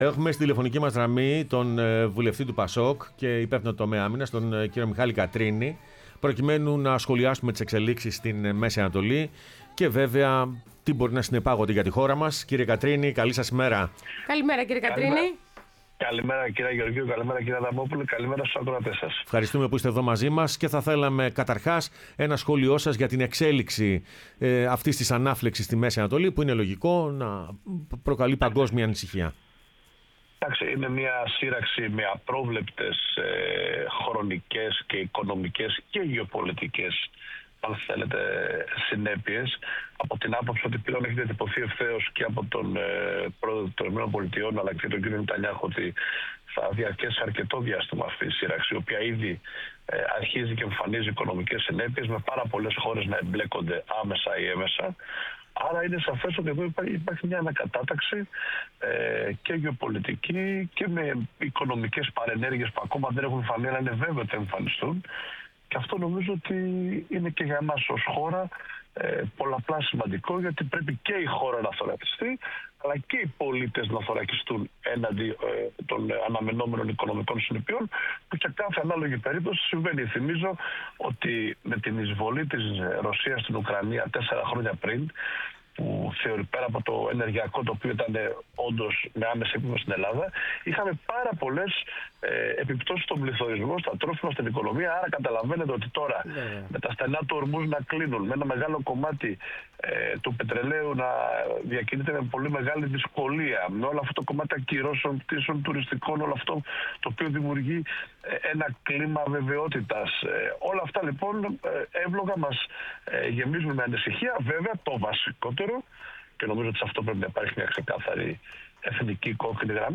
Ο Μιχάλης Κατρίνης βουλευτής ΠΑΣΟΚ, μίλησε στην εκπομπή Πρωινές Διαδρομές